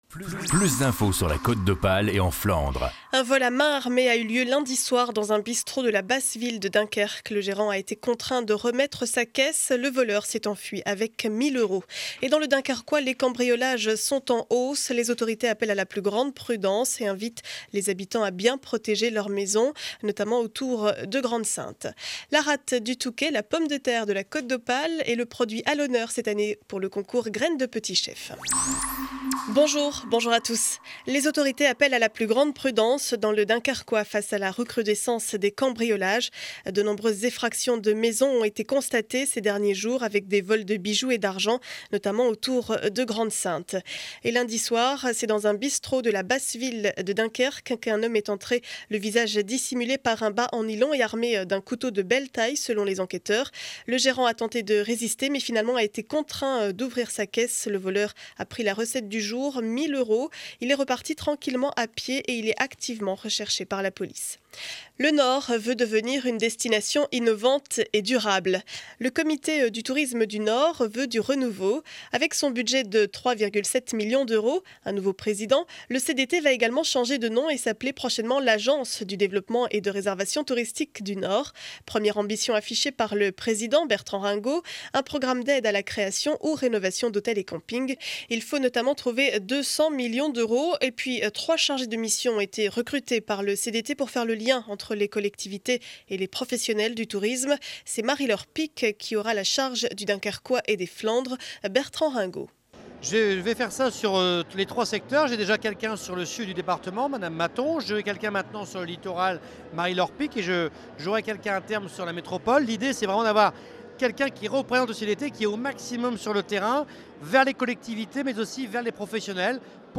Journal du mercredi 25 janvier 2012 12 heures édition du Dunkerquois.